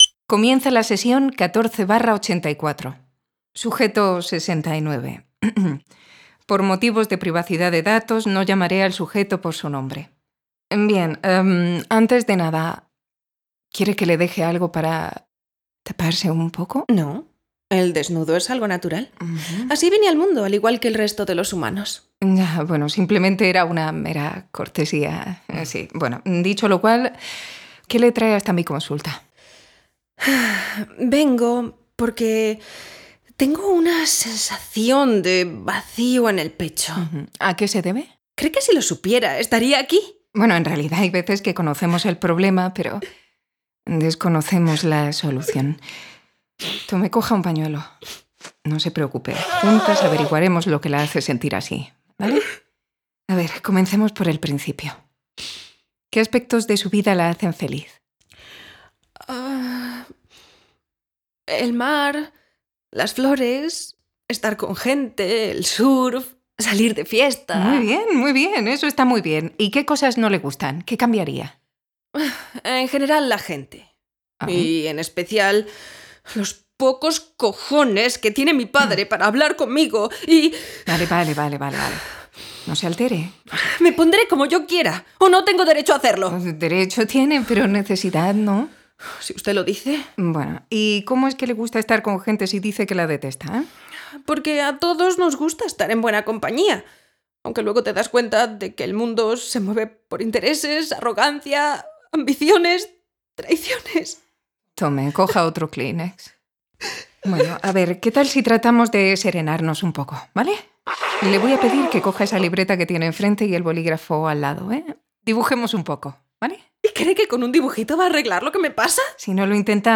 Format: Audio Drama
Voices: Full cast
Soundscape: Voices only